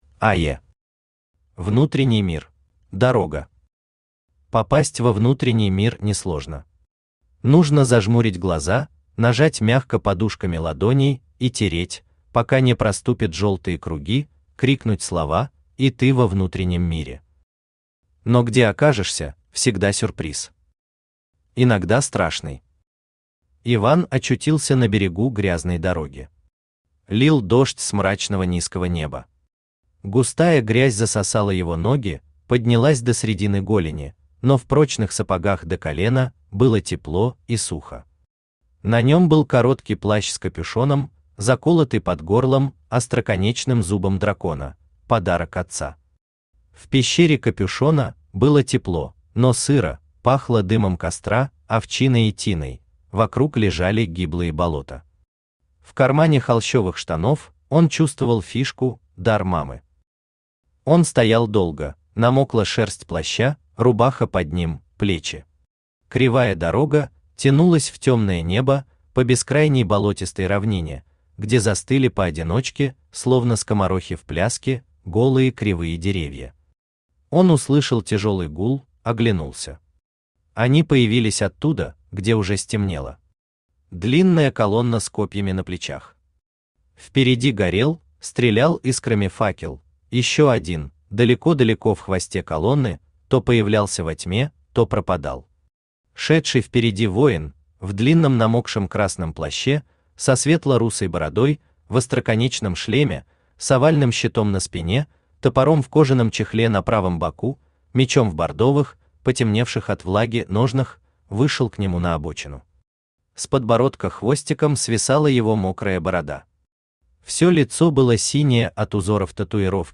Аудиокнига Внутренний мир | Библиотека аудиокниг
Aудиокнига Внутренний мир Автор АЕ Читает аудиокнигу Авточтец ЛитРес.